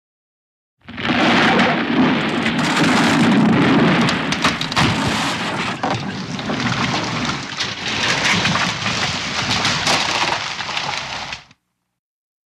IMPACTS & CRASHES - WOOD WOOD: EXT: Pushing trees over.